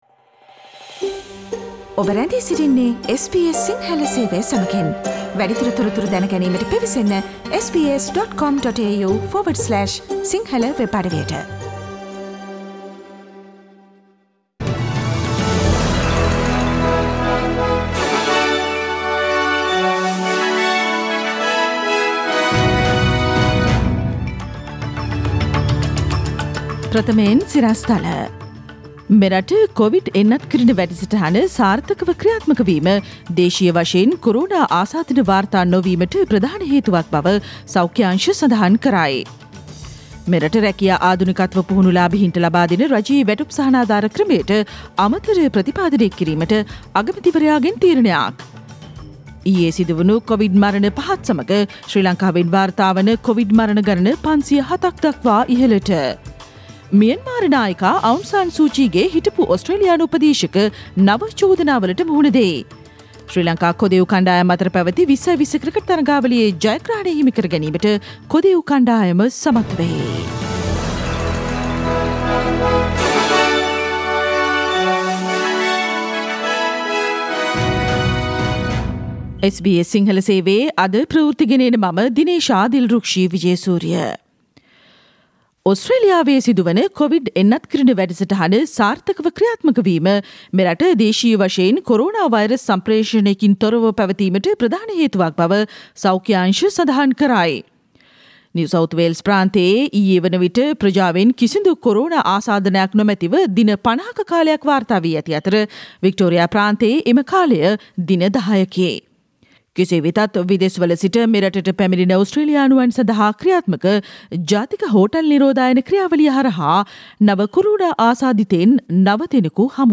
SBS සිංහල සේවයේ 2021 මාර්තු 9 වන අඟහරුවාදා වැඩසටහනේ ප්‍රවෘත්ති ප්‍රකාශය.